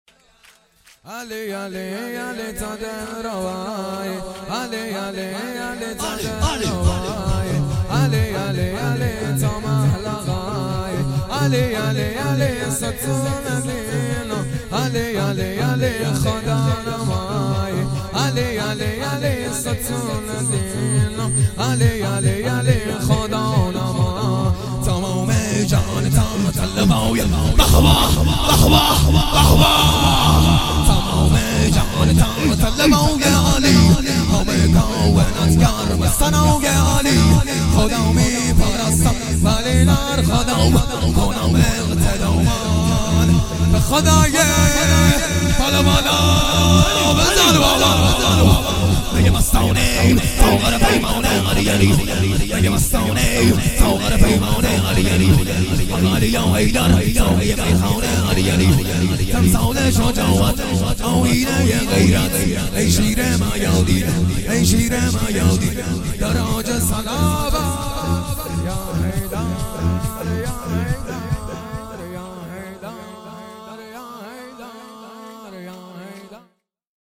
هیئت شیفتگان جوادالائمه علیه السلام مشهد الرضا
شور
ولادت امام علی ۱۳۹۸